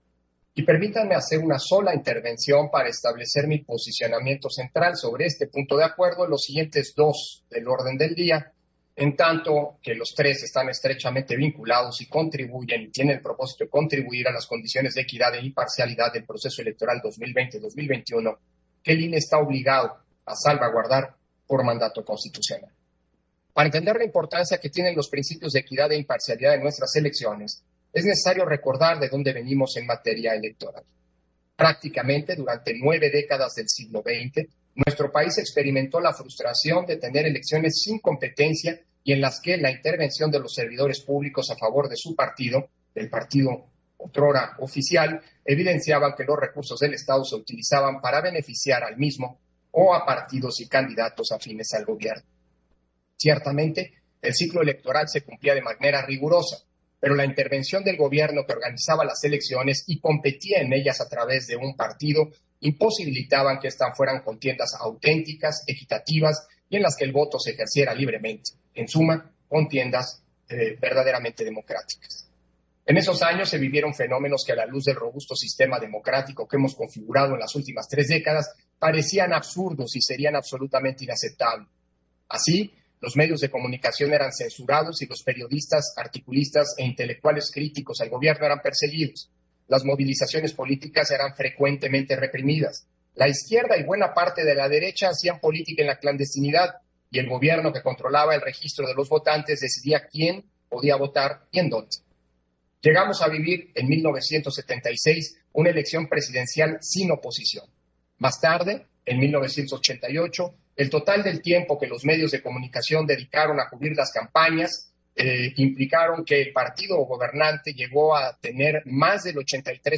211220_AUDIO_INTERVENCIÓN-CONSEJERO-PDTE.-CÓRDOVA-PUNTO-9-SESIÓN-EXT. - Central Electoral